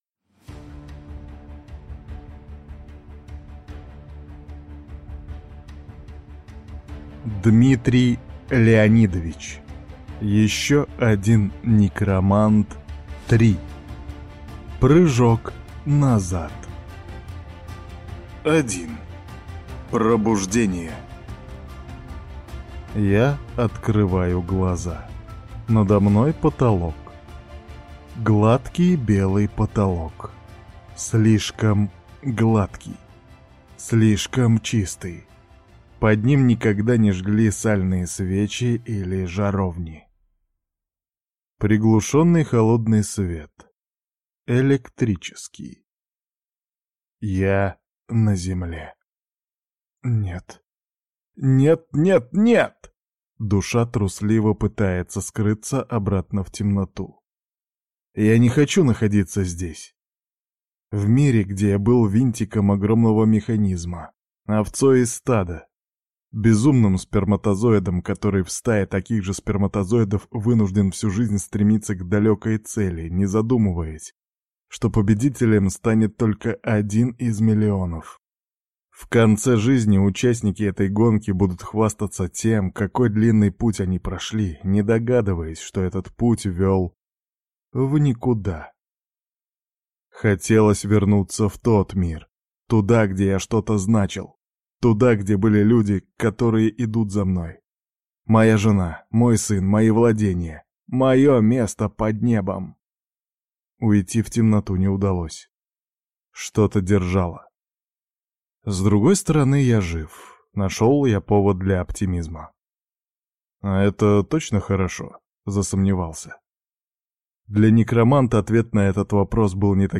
Еще один некромант - 3. Аудиокнига